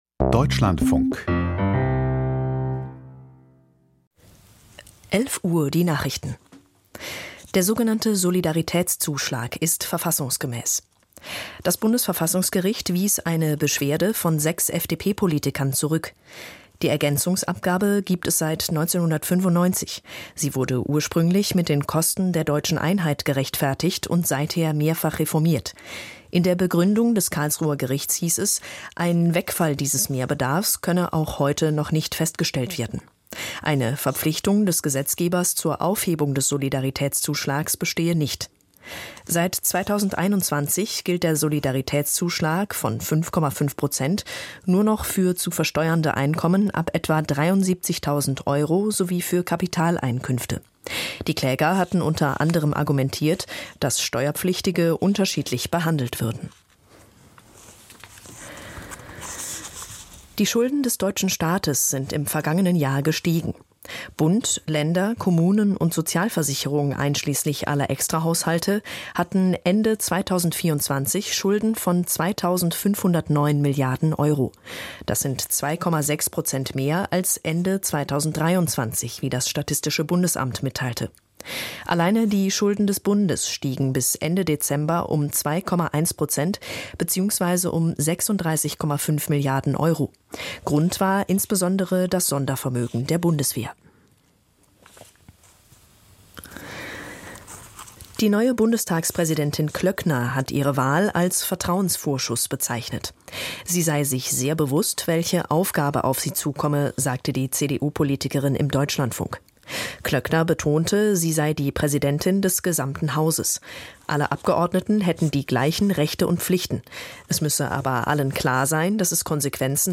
Die Deutschlandfunk-Nachrichten vom 26.03.2025, 11:00 Uhr